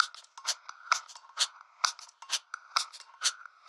Index of /musicradar/uk-garage-samples/130bpm Lines n Loops/Beats
GA_BeatErevrev130-04.wav